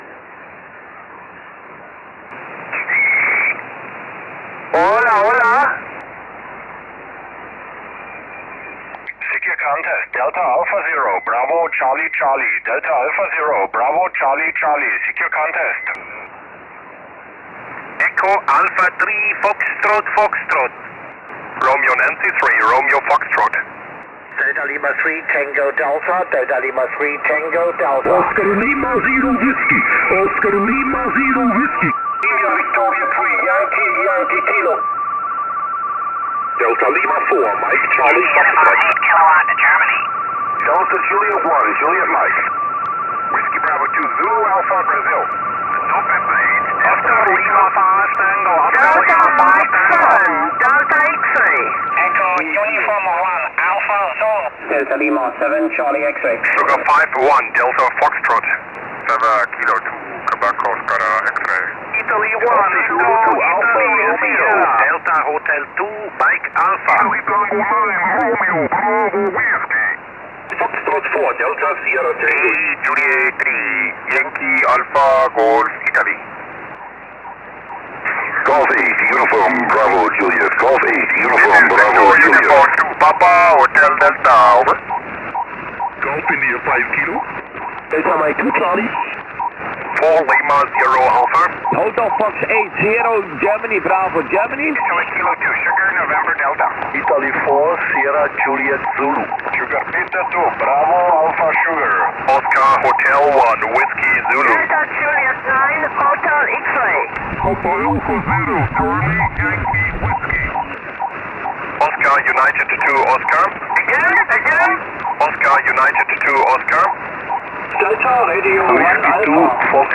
Die Aufgabe war erneut, aus einem SSB-Pileup so viele Rufzeichen wie möglich korrekt zu loggen.